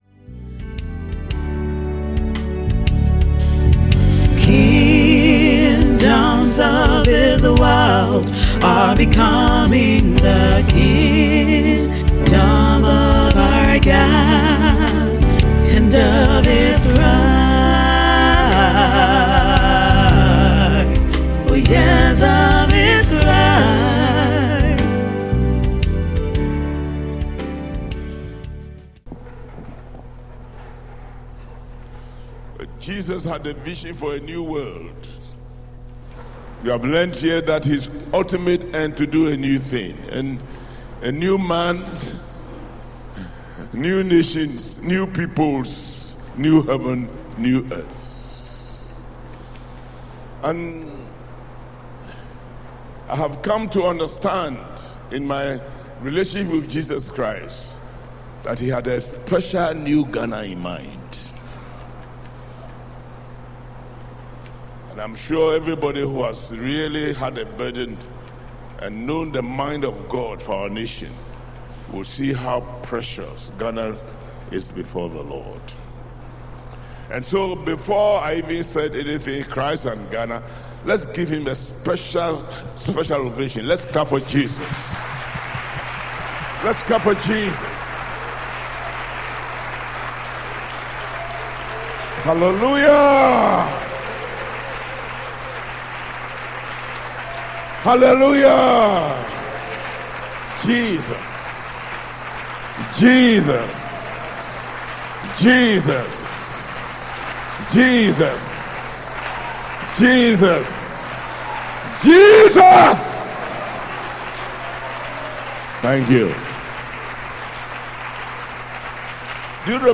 SERMON TITLE: Christ and Africa